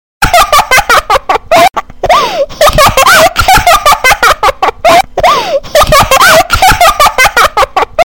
ŚCIĄGNIJ Cute Laugh 2